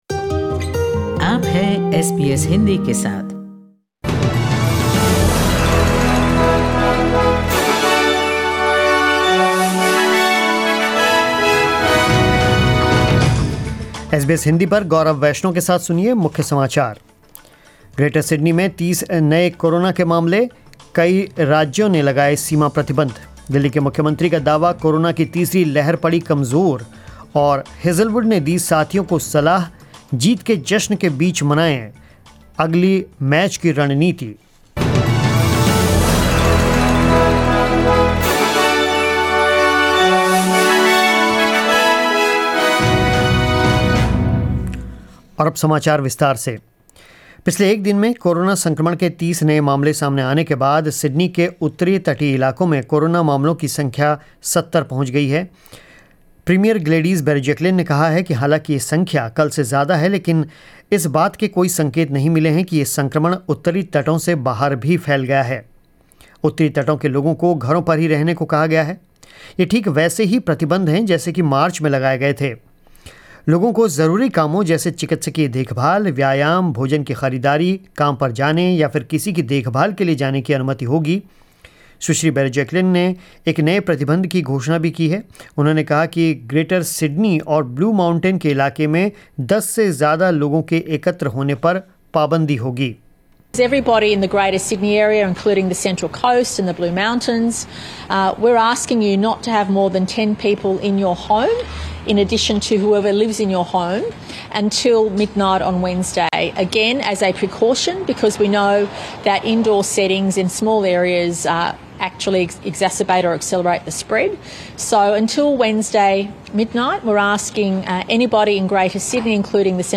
News in Hindi 20 December 2020